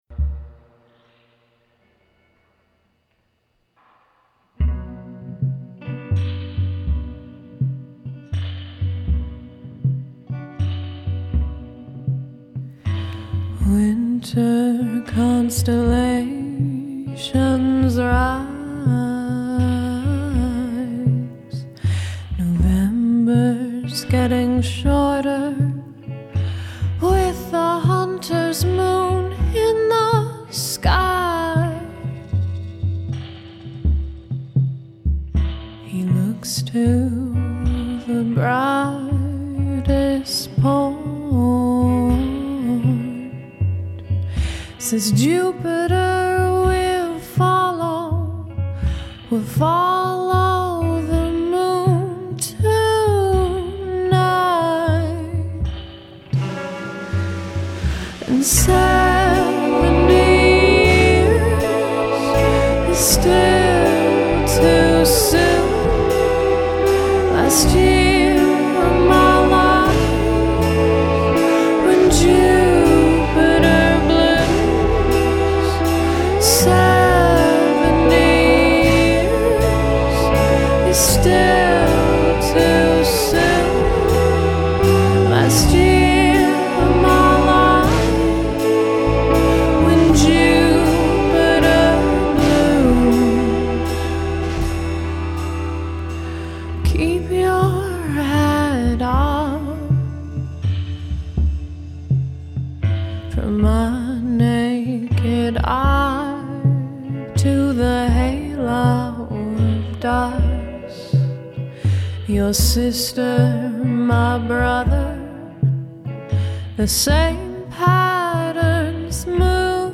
For a sparsely instrumented song, the vibe is rich and dark.